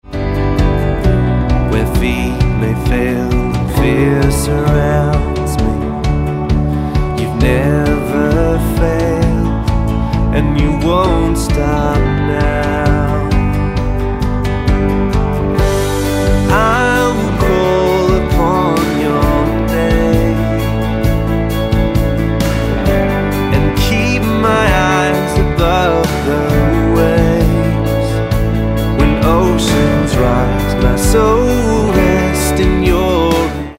Bm